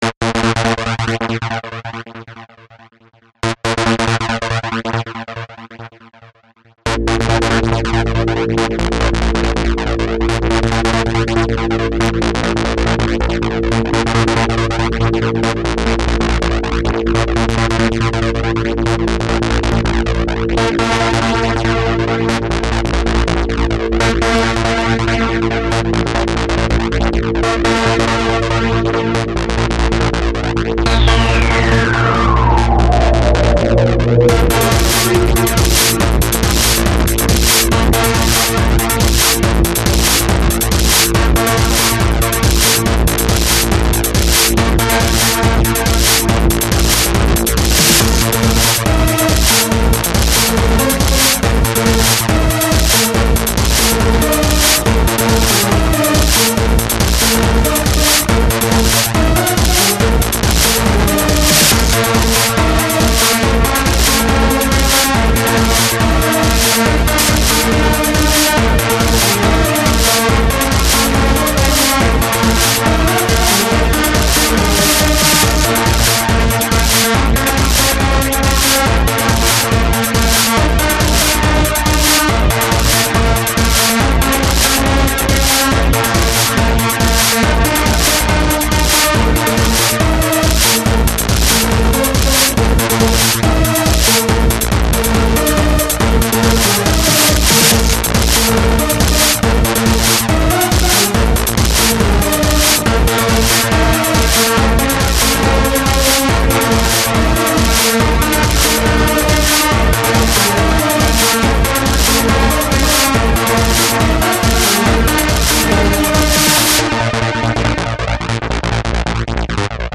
Yes,it ends too fast,but still.
Yay, EBM :3
This song is BEGGING for a background pad.
The synth that comes in at 0:47 is a bit cheesy though, It's a simple saw without anything special to it, but apart from that, the song is pretty nice.